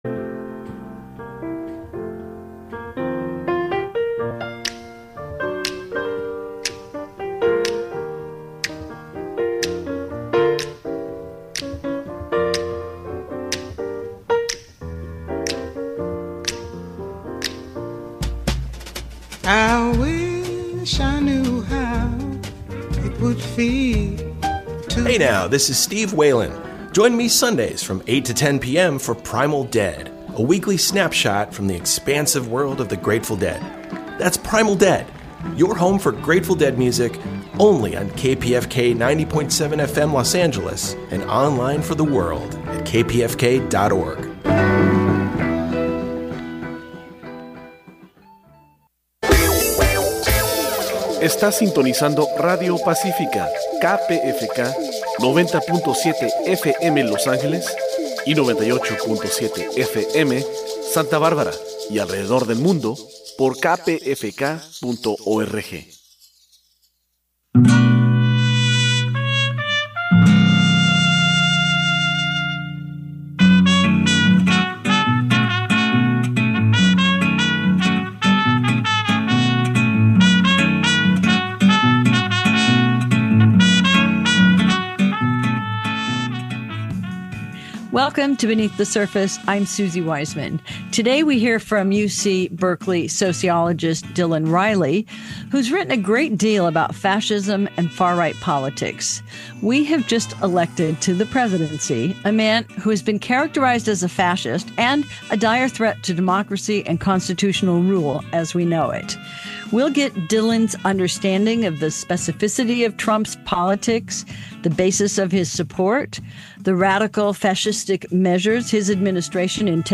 Beneath the Surface features interviews with leading thinkers and activists on the important issues of the day, with a focus on deeper analysis.